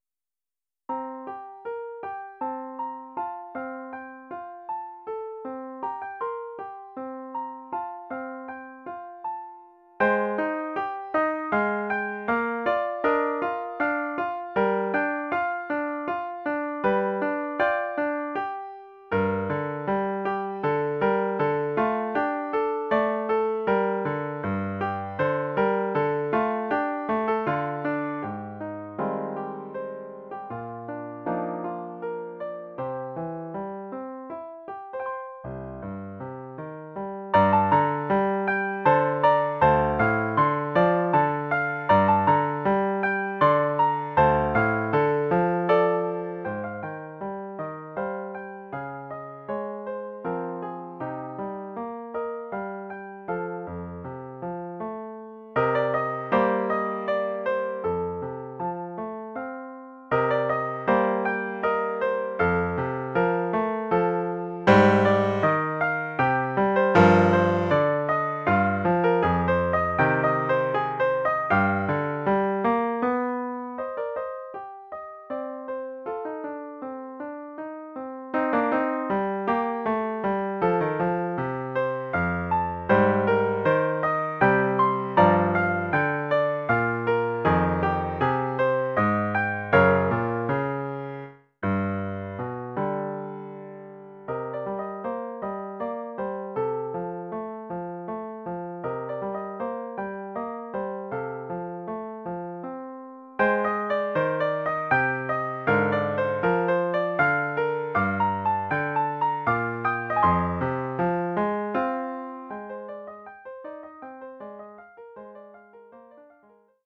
Collection : Piano
Oeuvre pour piano solo.